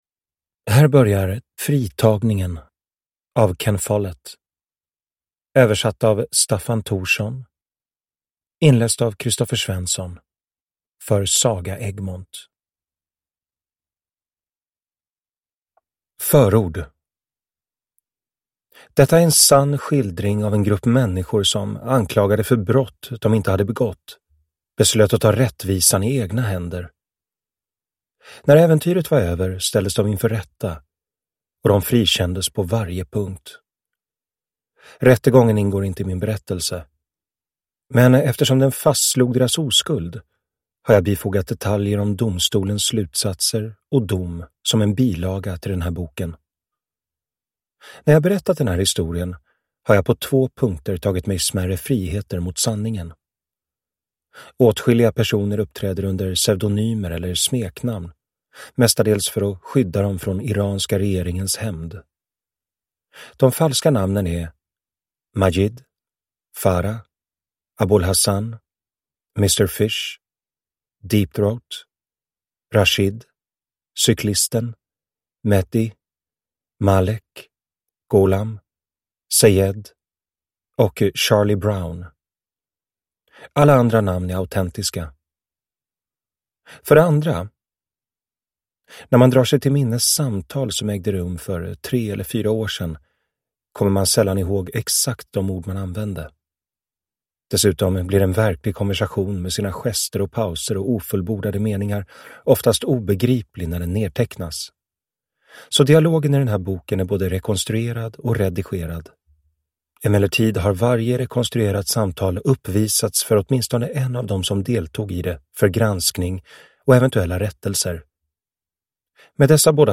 Fritagningen (ljudbok) av Ken Follett